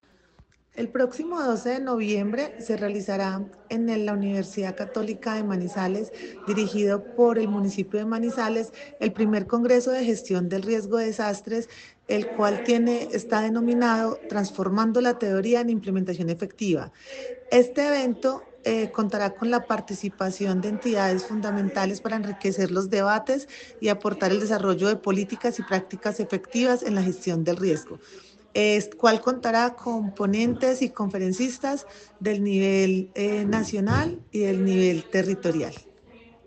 Paula Villamil Rendón, jefe de Gestión del Riesgo de Caldas.